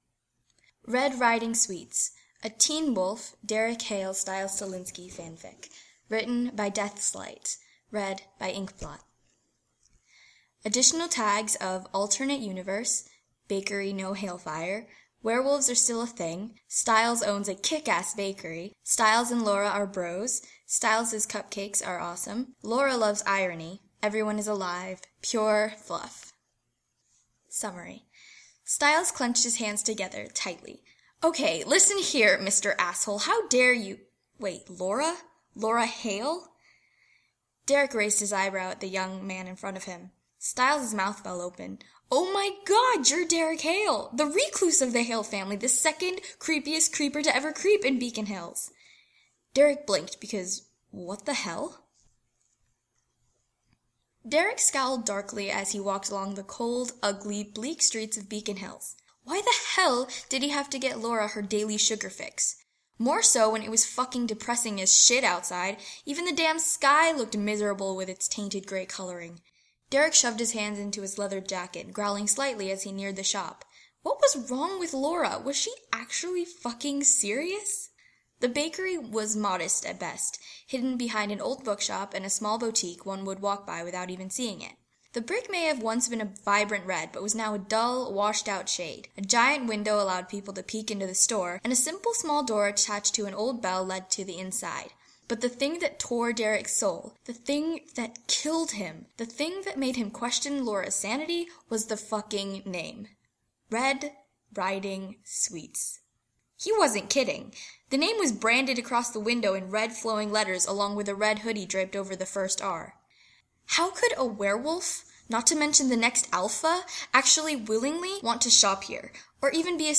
[podfic] Red Riding Sweets by DeathsLights